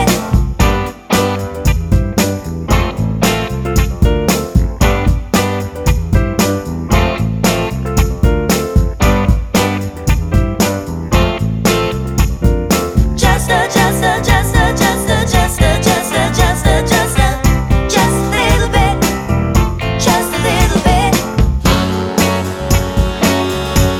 no Backing Vocals Soul / Motown 2:28 Buy £1.50